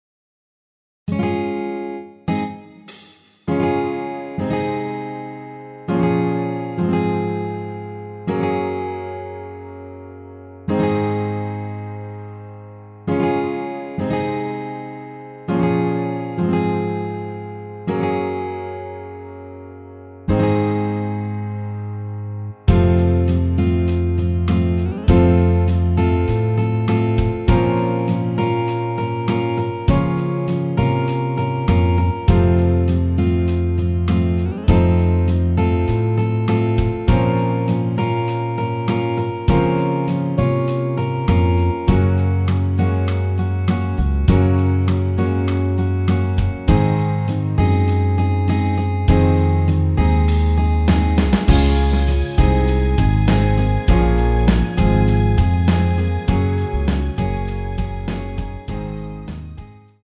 원키에서(-2)내린 MR입니다.
전주 없이 시작 하는 곡이라 1마디 드럼(하이햇) 소리 끝나고 시작 하시면 됩니다.
Db
앞부분30초, 뒷부분30초씩 편집해서 올려 드리고 있습니다.
중간에 음이 끈어지고 다시 나오는 이유는